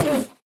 Minecraft / mob / endermen / hit1.ogg
hit1.ogg